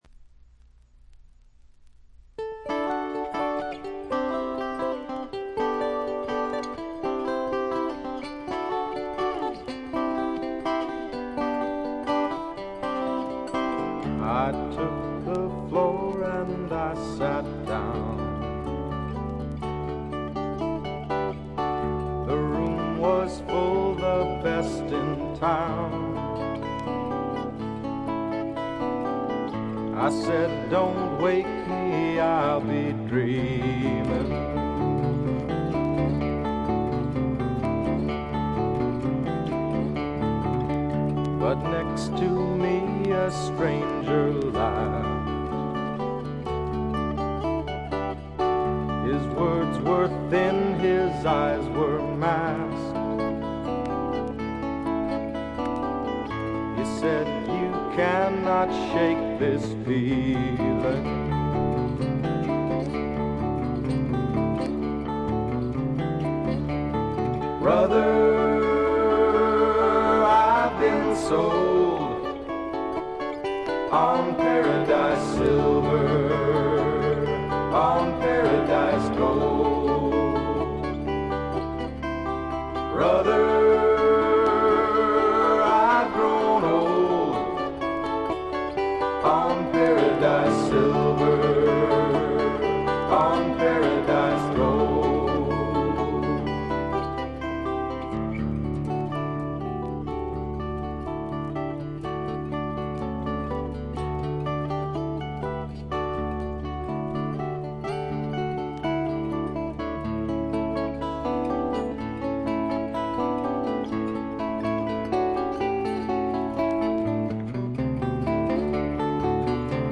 個人的にはジャケットの写真にあるようにフルアコ2台のエレクトリック・ギターの音が妙にツボに来ます。
試聴曲は現品からの取り込み音源です。
Guitar, Banjo, Vocals